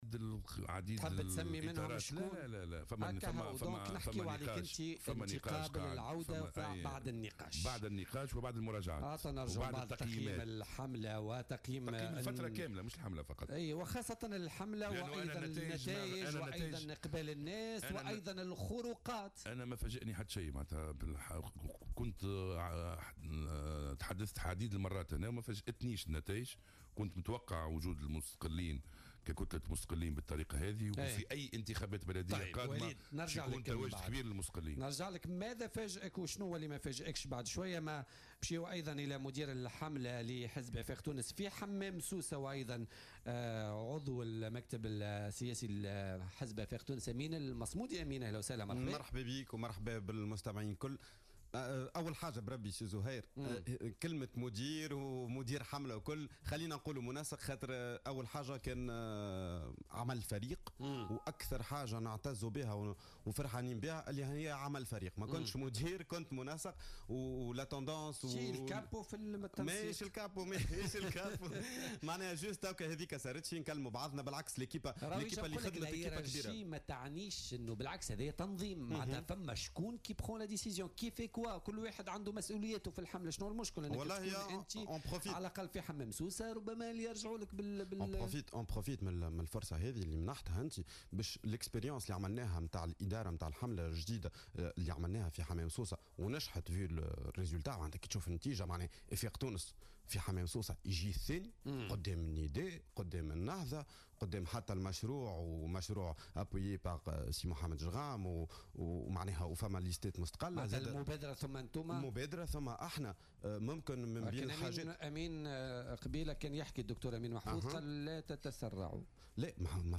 أكد النائب بالبرلمان وليد جلاد ضيف بوليتيكا اليوم الأربعاء 9 ماي 2018 أنه لم يتفاجأ بنتائج الانتخابات البلدية الأولية.